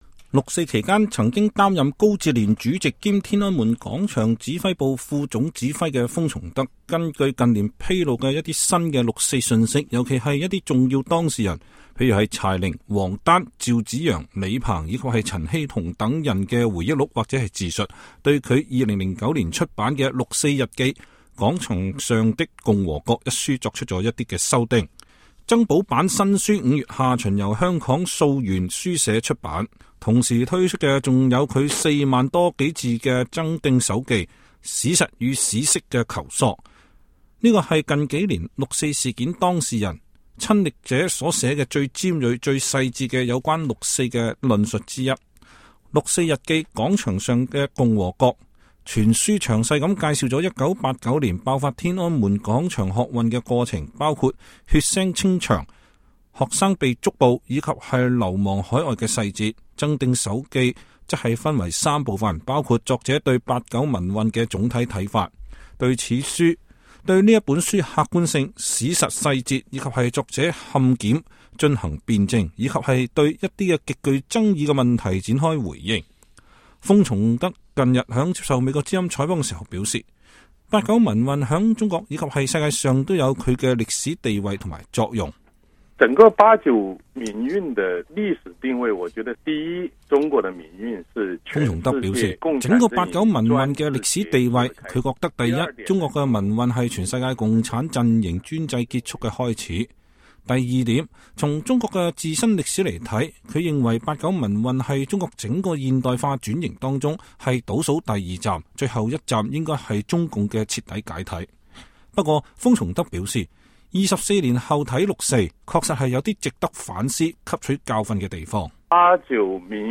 封從德近日在接受美國之音採訪時表示，八九民運在中國和世界上都有其歷史地位和作用。